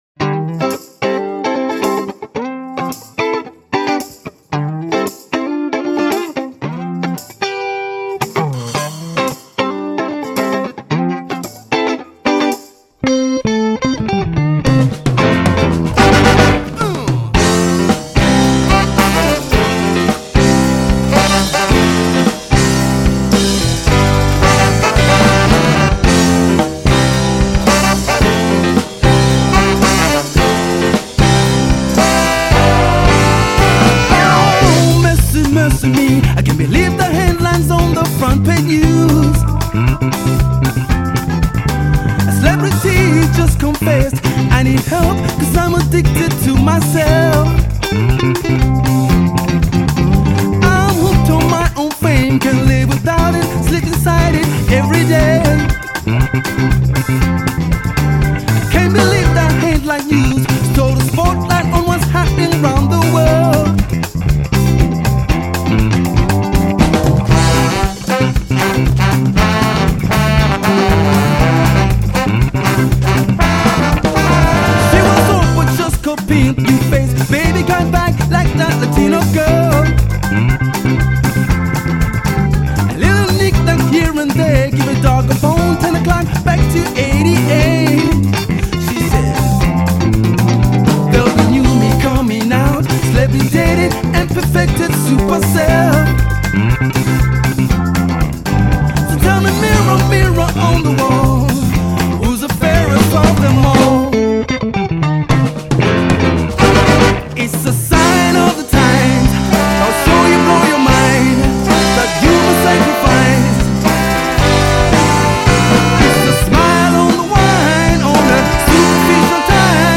Tight horn riffs, dansable grooves, catchy songs.